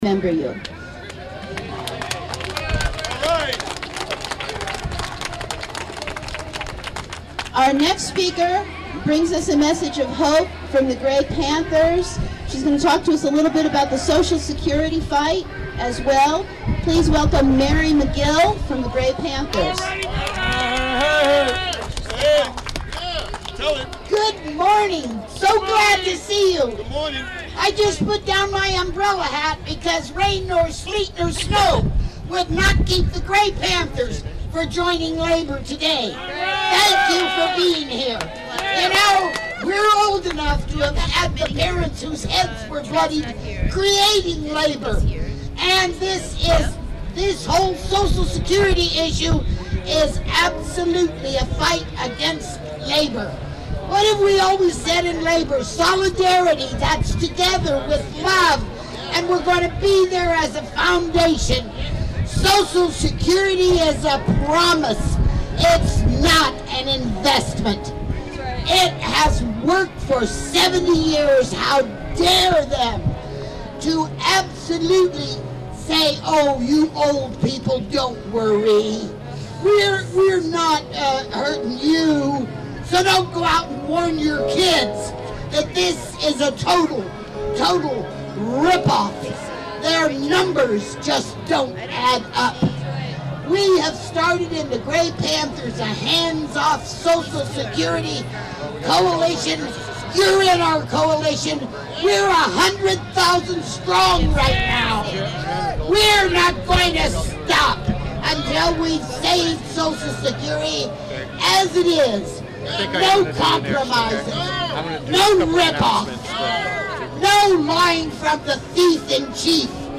sounds from the March 19 labor rally at Dolores Park, San Francisco, on the second anniversary of the war on Iraq
§labor anti-war rally, part 2